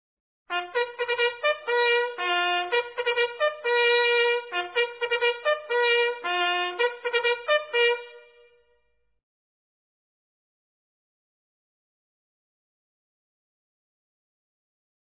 Familiar Bugle Calls